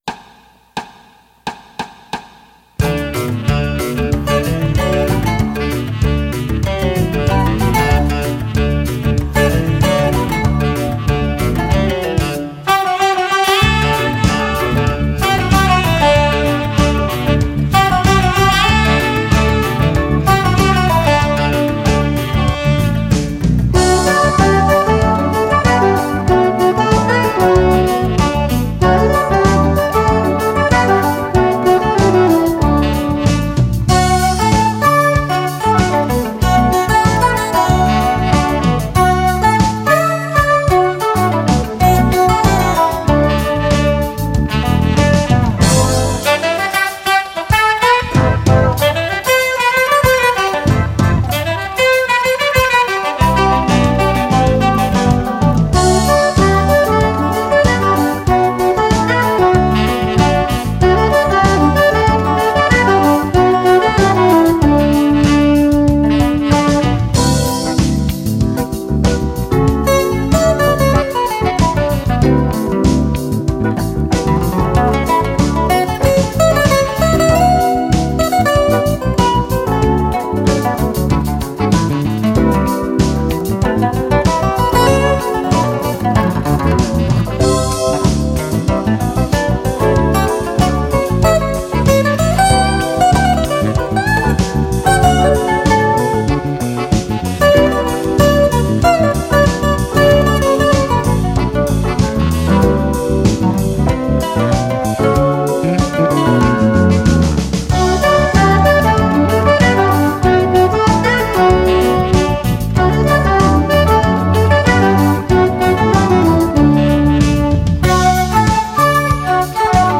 electric guitar solo.
saxophone and flute.